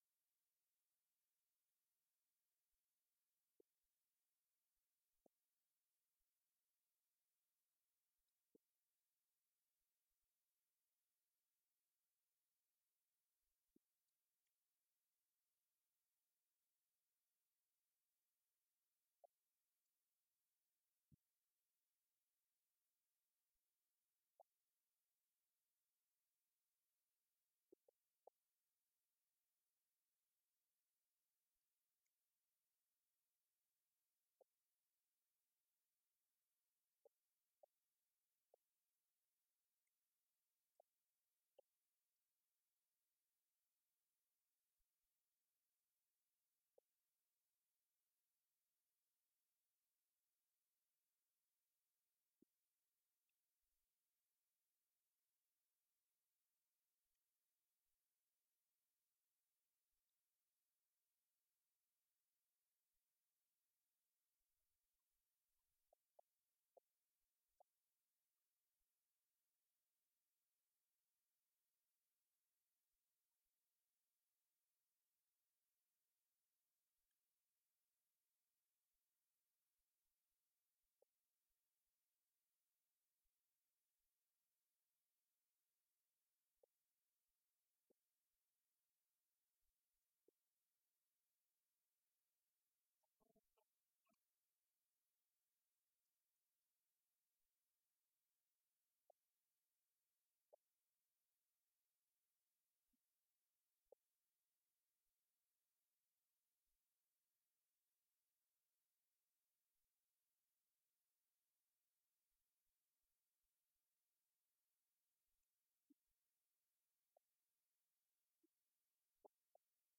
O Presidente do Governo falava na assinatura de um contrato ARAAL de colaboração técnico-financeira entre o Governo dos Açores e a Câmara de Municipal da Horta, com vista à construção de um equipamento de serviços sociais na freguesia da Feteira, no valor de 85 mil euros.